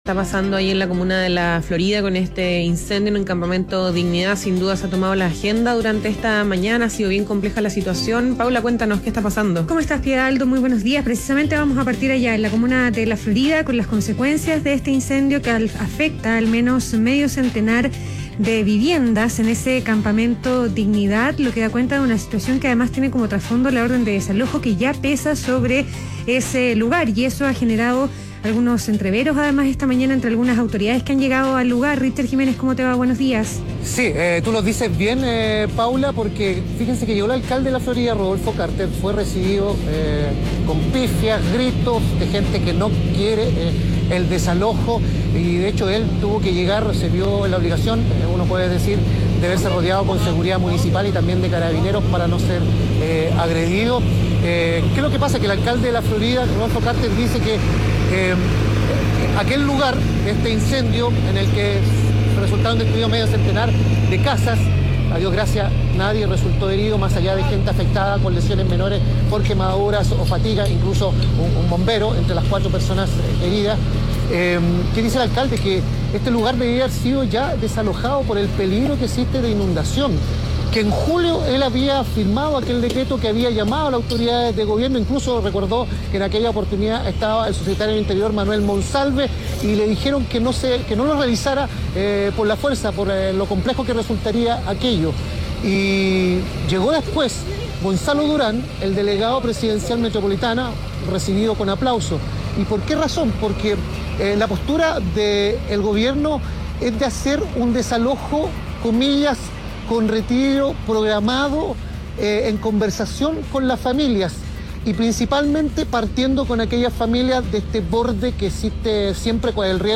Ambas autoridades se reunieron en la toma, donde sostuvieron una conversación que de a poco se fue acalorando, a raíz de una orden de desalojo que pesa sobre el lugar.
Sin embargo, estas diferencias quedaron en evidencia en la conversación que ambos sostuvieron, y que Radio ADN logró captar.